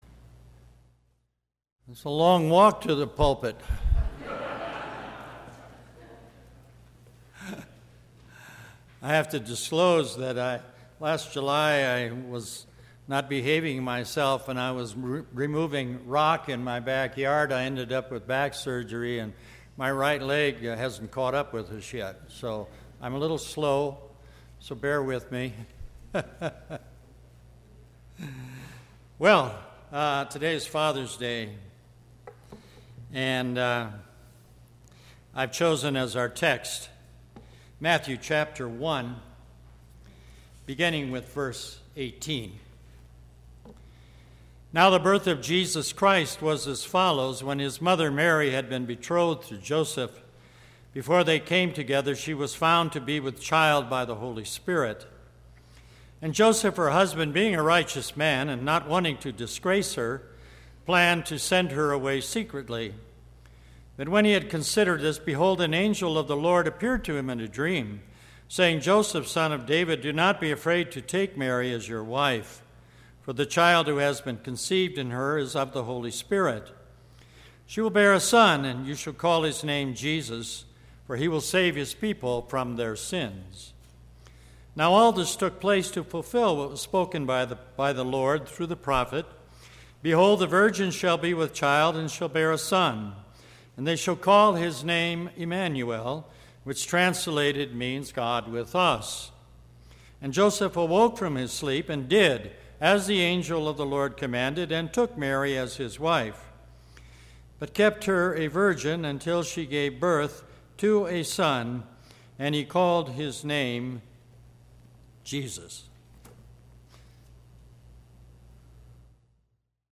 Third Sunday after Trinity
Sermon – 6/17/2018